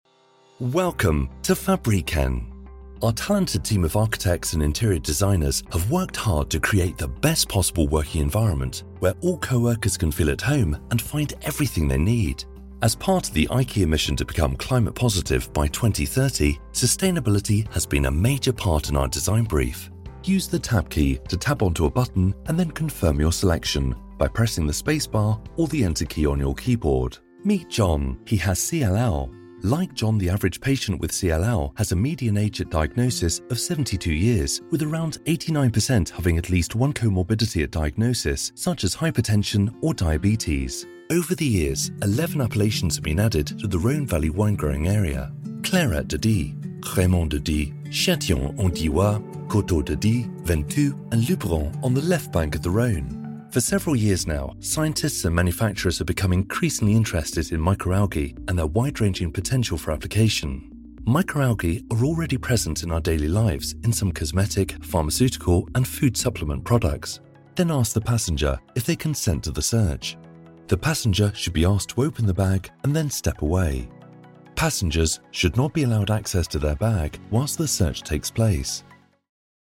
Male British Voiceover with warm, luxurious, adaptable, velvety and assured tone.
Sprechprobe: eLearning (Muttersprache):
I have my own professional Studio with Voiceover Booth, Neumann TLM103 microphone, Genelec Speakers plus Beyer Dynamic Headphones for monitoring.